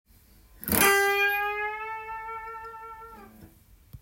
①のチョーキングはチョップ奏法と言われる
チョーキング前に数本の弦を巻き込んで弾いていきジャキジャキした音の